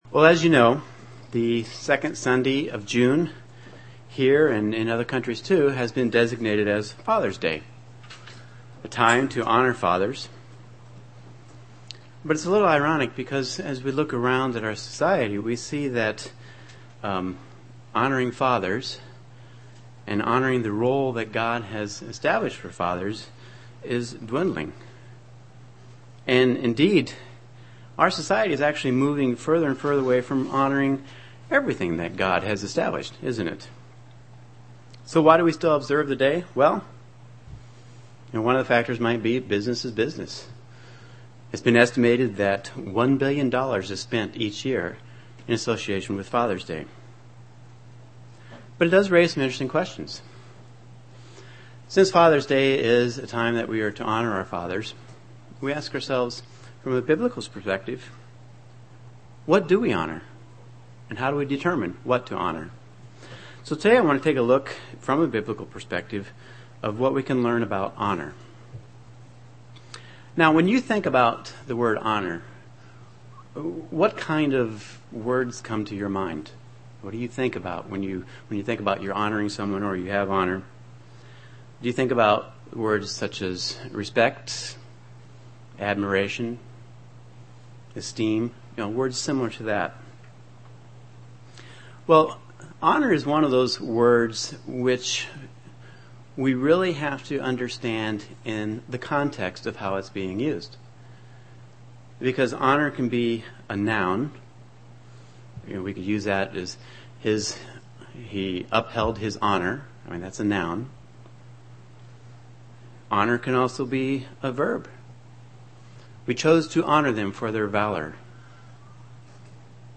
UCG Sermon Studying the bible?
Given in Chicago, IL